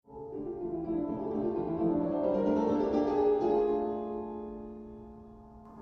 Out of nothing comes this haunting, quite fast theme. To me it’s like something distressing, thoughts and feelings that you would rather stay away coming at you: